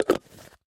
Крышку на термос надели и закрутили